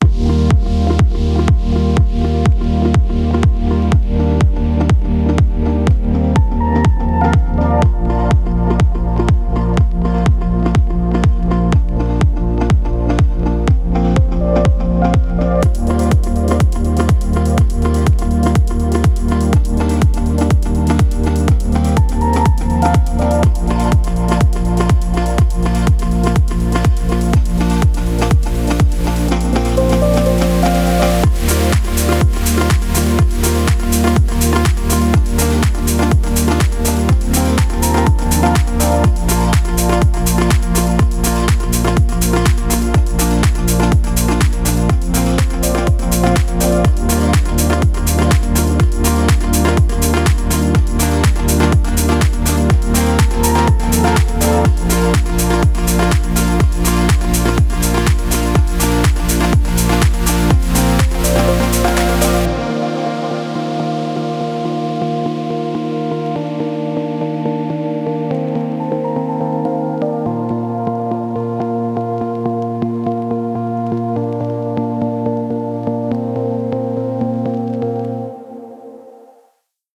Music / House
melodichouse
Very chill vibe!